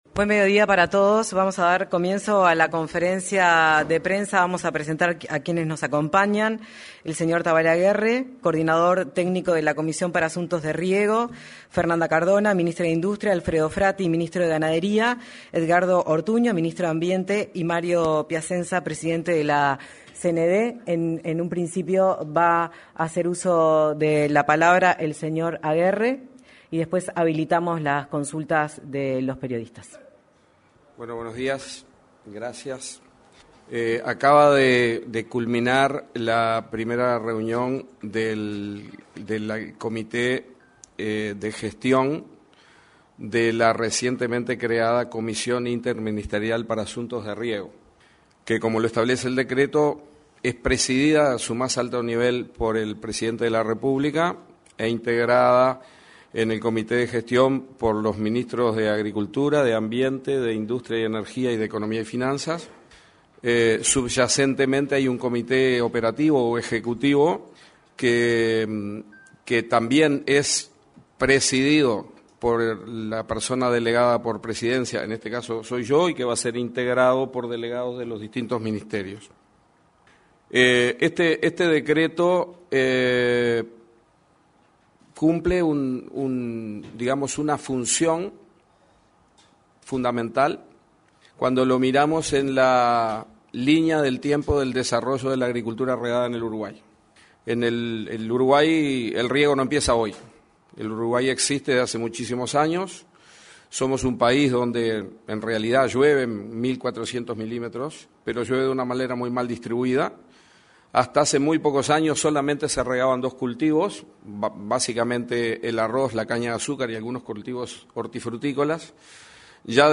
Conferencia de prensa luego de reunión de la Comisión para Asuntos de Riego
Conferencia de prensa luego de reunión de la Comisión para Asuntos de Riego 18/09/2025 Compartir Facebook X Copiar enlace WhatsApp LinkedIn Se realizó, en la Torre Ejecutiva, una conferencia de prensa acerca de la Comisión para Asuntos de Riego. En la oportunidad, se expresó el coordinador técnico de dicho ámbito, Tabaré Aguerre.